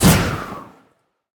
Minecraft Version Minecraft Version 25w18a Latest Release | Latest Snapshot 25w18a / assets / minecraft / sounds / mob / breeze / wind_burst3.ogg Compare With Compare With Latest Release | Latest Snapshot
wind_burst3.ogg